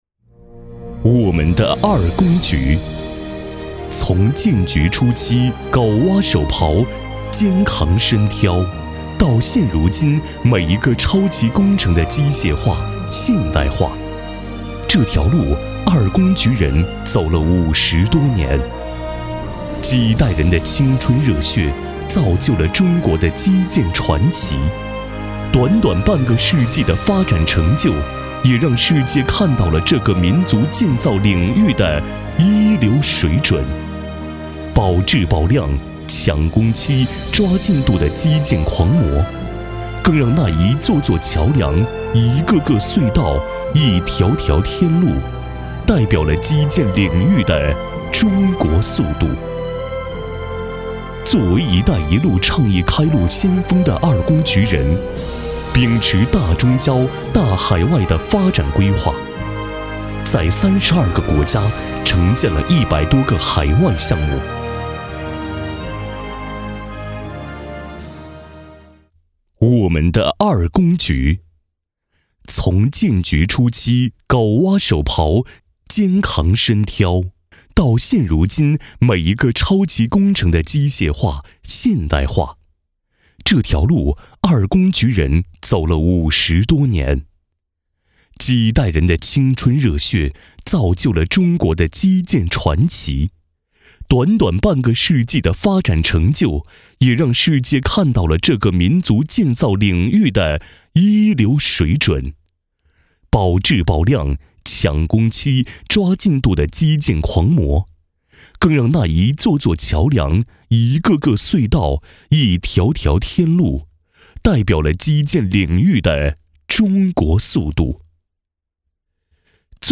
特点：大气浑厚 稳重磁性 激情力度 成熟厚重
风格:磁性配音